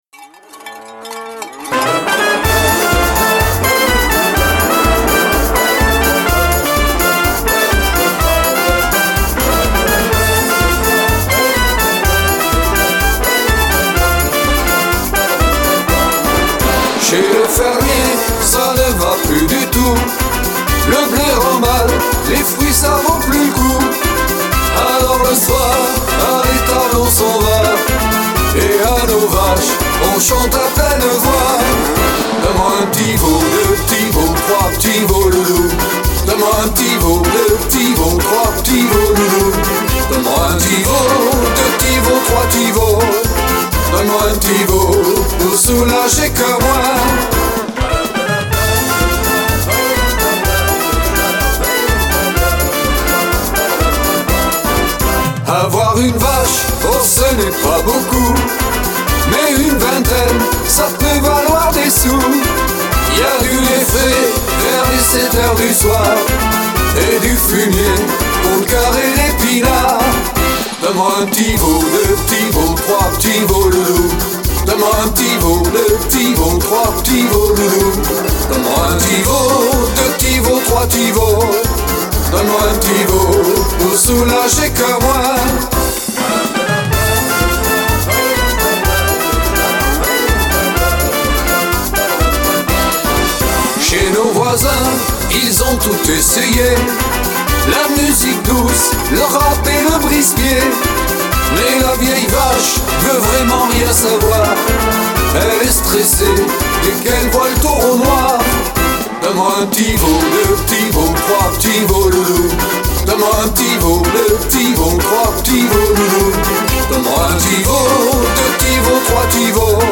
version  chantée